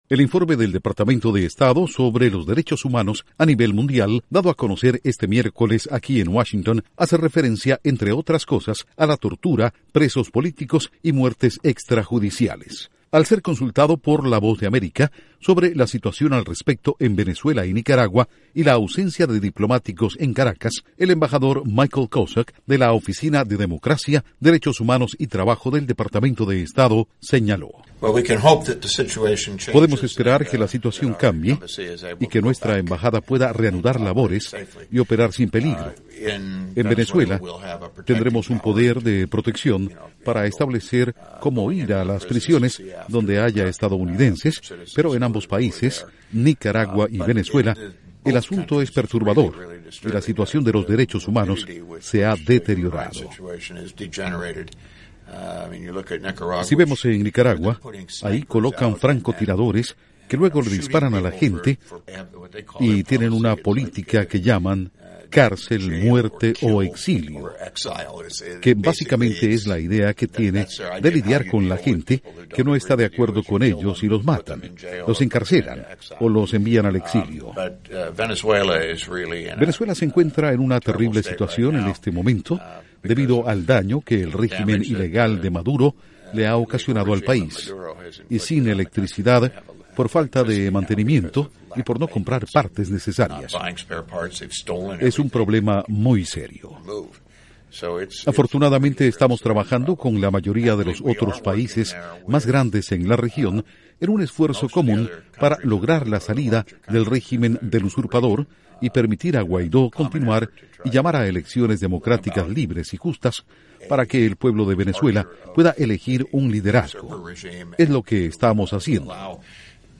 Duración: 2:16 Con audios de Michael Kozak/Departamento de Estado